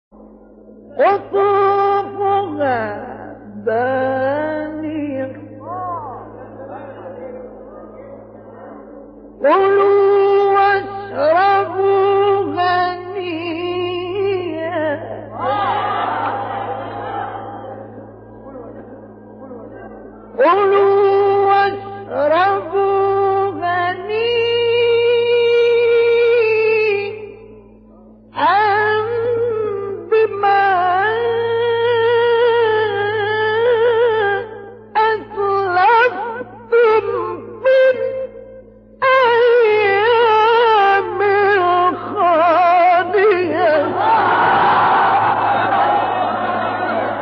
گروه فعالیت‌های قرآنی: فرازهای صوتی از قراء برجسته جهان اسلام را می‌شنوید.
مقطعی از محمد صدیق منشاوی/ سوره علق